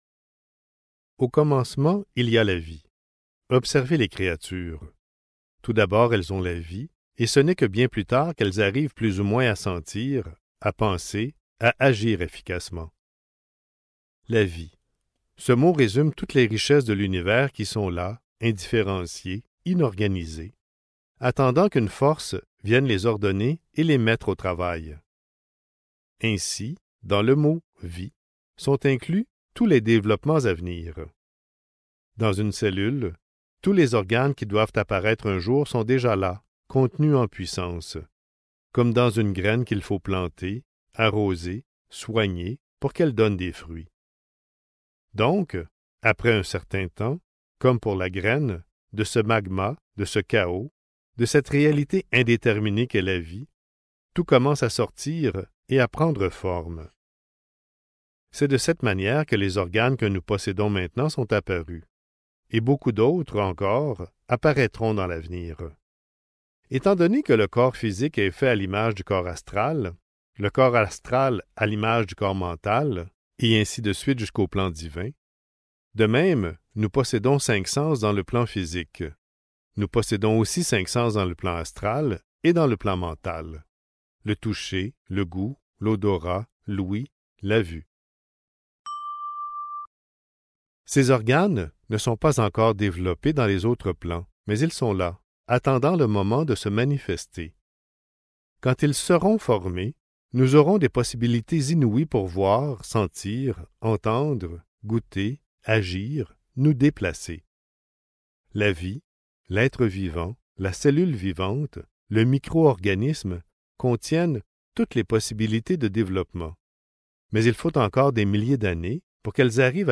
Harmonie et santé (Livre audio | CD MP3) | Omraam Mikhaël Aïvanhov